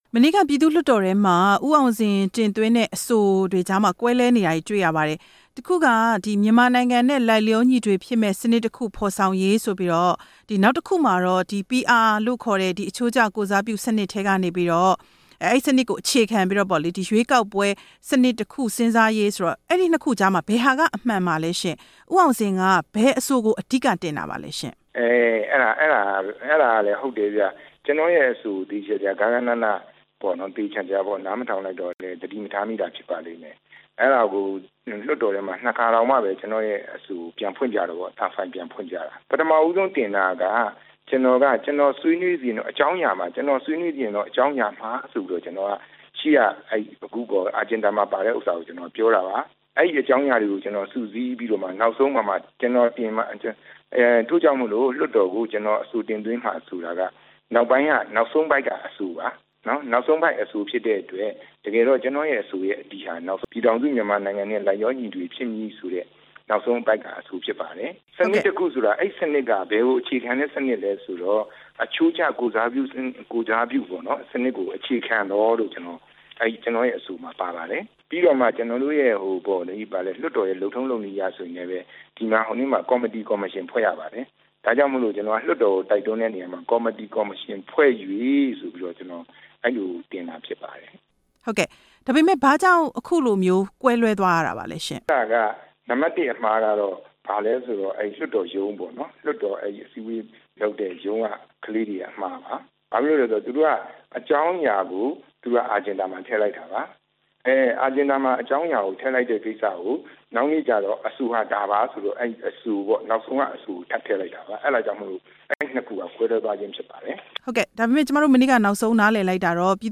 PR အဆိုတင်သွင်းသူ လွှတ်တော်အမတ် ဦးအောင်ဇင်နဲ့ ဆက်သွယ်မေးမြန်းချက်